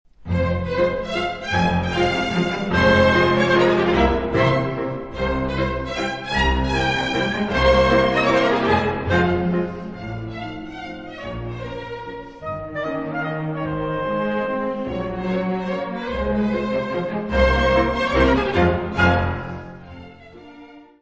violino
trompete
Área:  Música Clássica